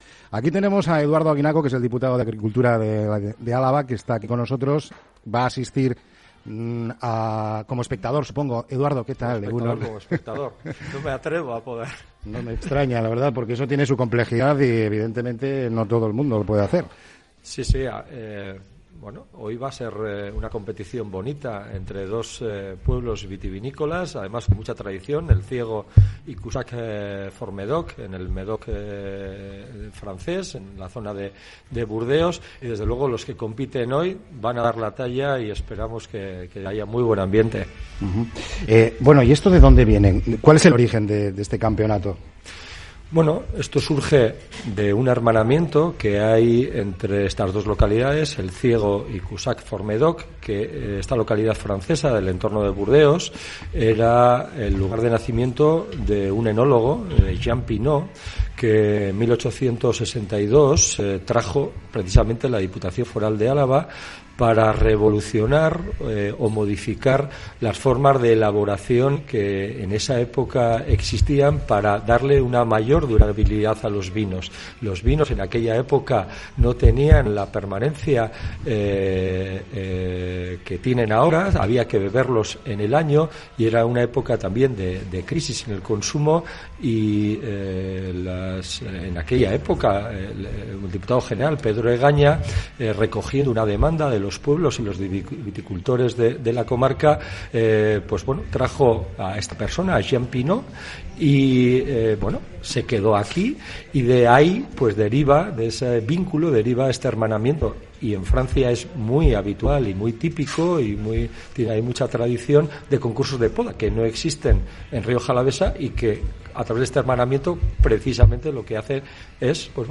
Con motivo de la celebración de esta jornada, diferentes personalidades han pasado por los micrófonos de Onda Vasca, como es el caso del Diputado de Agricultura de Álava Eduardo Aguinaco. A pesar de las dificultades que debe afrontar el sector, encara con optimista un futuro que están construyendo jóvenes con talento que están incorporando nuevos métodos e ideas.